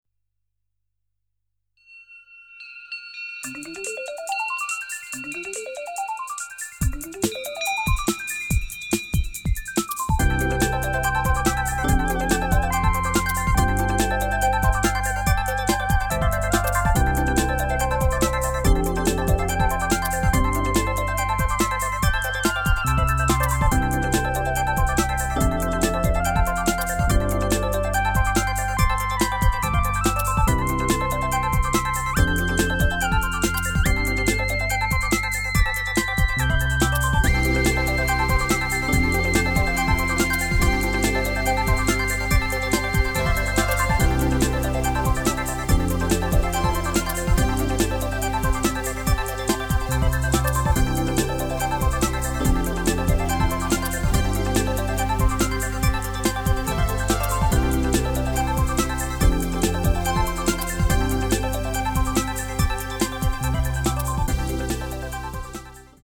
オクターバー自体は今無いので、簡単にDAWで１オクターブ低い音をサブウーファー側に合成。
ミッド域の被りが気になるので、-24db/oct、80Hzを４段かませてます。
なかなか面白い音に！。